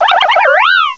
cry_not_buneary.aif